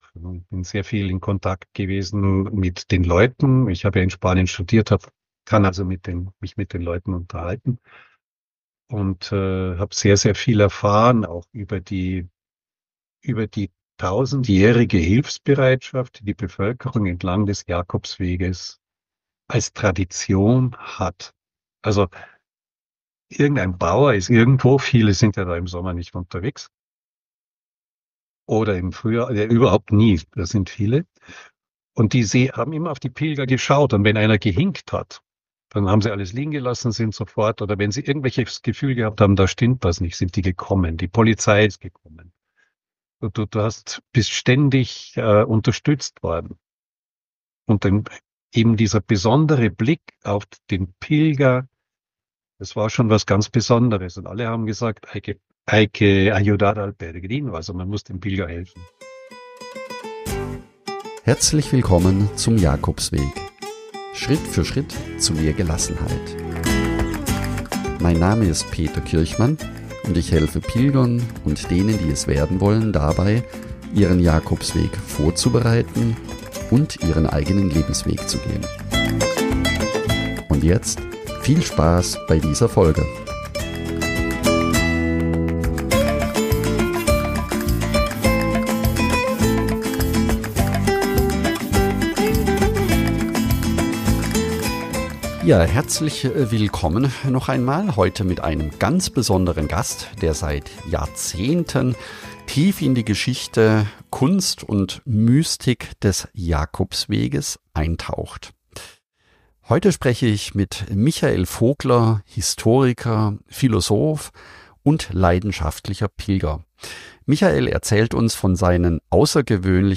Dieses Gespräch ist voller Inspiration und lädt dazu ein, den Camino nicht nur als Wanderweg, sondern als Weg zu sich selbst zu verstehen. Da die Inhalte so reichhaltig und vielfältig sind, haben wir das Interview auf zwei Teile aufgeteilt.